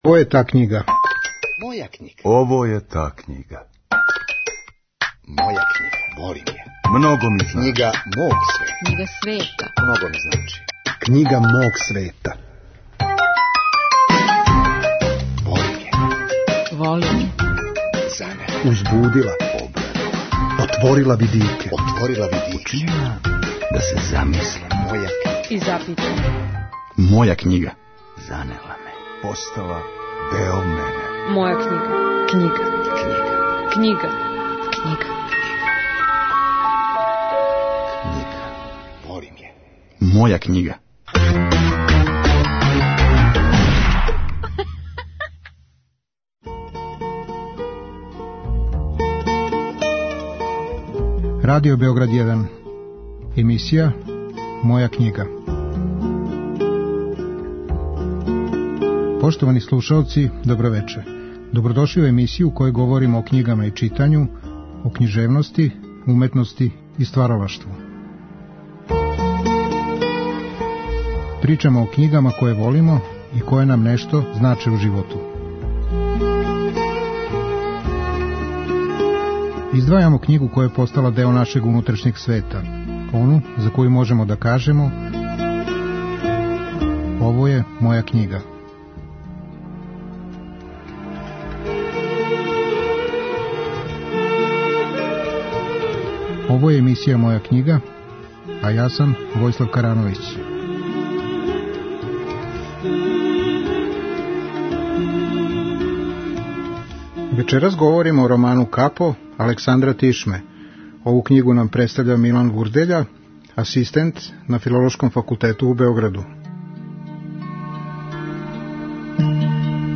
Емисија о књигама и читању, о књижевности, уметности и стваралаштву. Гости су људи различитих интересовања, различитих занимања и професија. Сваки саговорник издваја књигу коју воли, ону која му је посебно значајна и за коју може да каже: ово је моја књига.